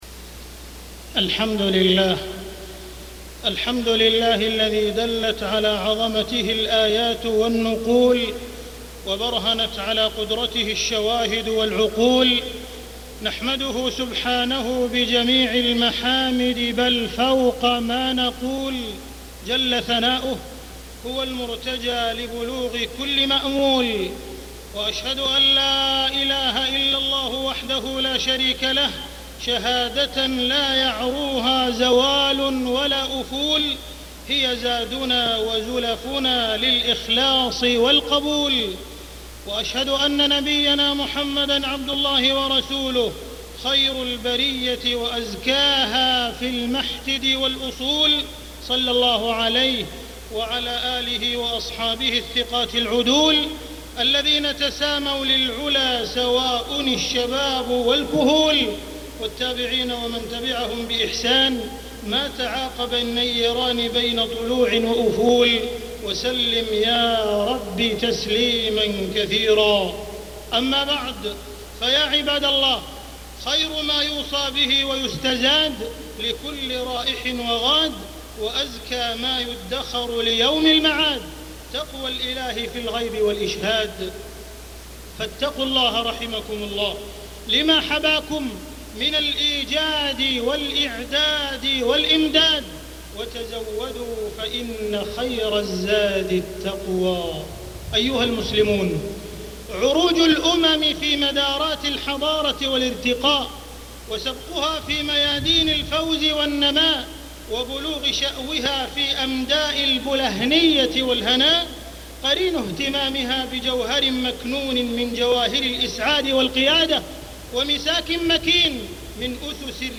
تاريخ النشر ١٧ جمادى الأولى ١٤٢٦ هـ المكان: المسجد الحرام الشيخ: معالي الشيخ أ.د. عبدالرحمن بن عبدالعزيز السديس معالي الشيخ أ.د. عبدالرحمن بن عبدالعزيز السديس لمحات في استثمار الإجازات